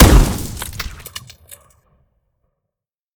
weap_romeo870_fire_plr_02_db.ogg